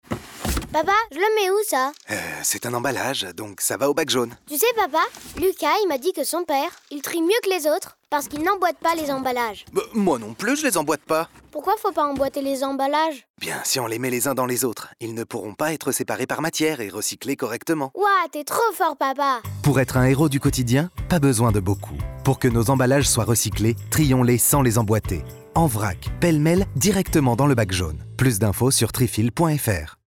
Spot radio - je trie sans emboîter